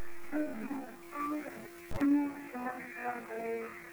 Here's A Few EVP's Featuring Sing Song Voices & Music